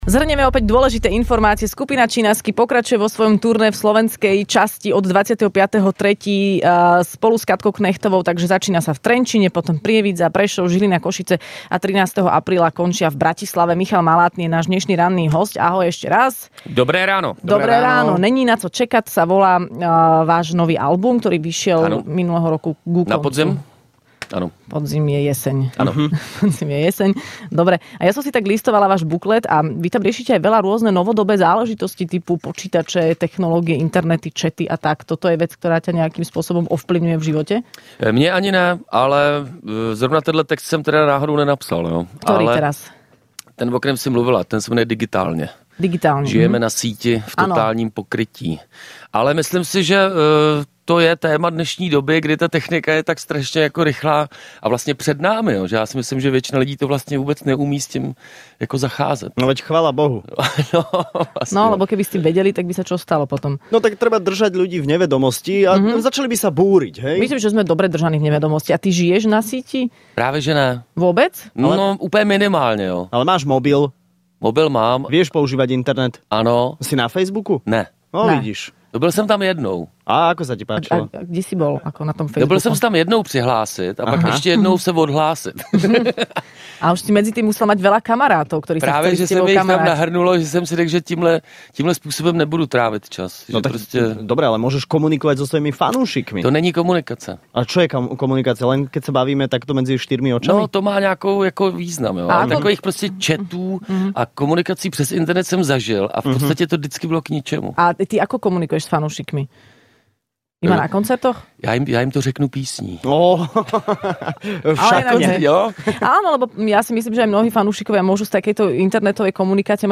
Skupina Chinaski prišla do Rannej šou predstaviť svoj nový album